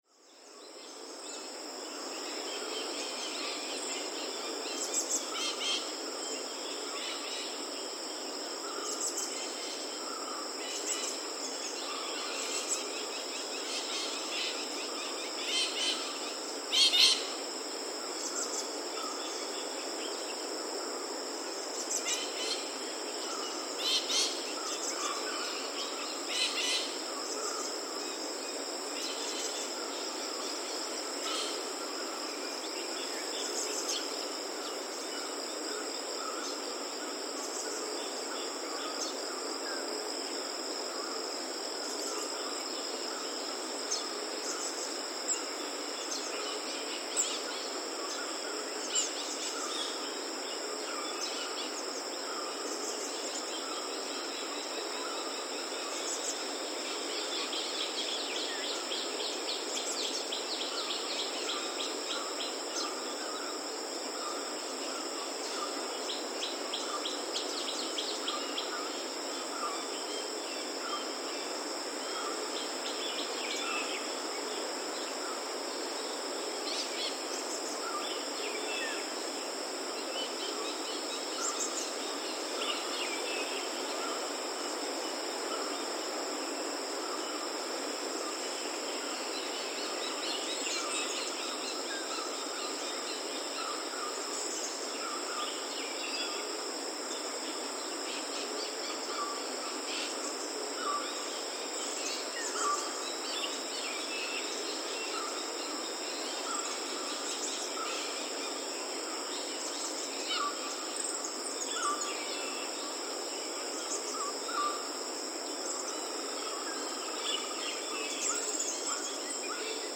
There is a  rich mix of sounds of birds confined to the hills, and widely distributed highland birds, together with those of an occasional mammal.
Insect sounds may be loud at different times in the day.
Morning Soundscapes
(Audio-technica AT4021x2 in ORTF stereo on Zoom F4)
(Note: wind sound in background is heavy due to monsoon winds in June)
Knuckles-2-1_ORTF.mp3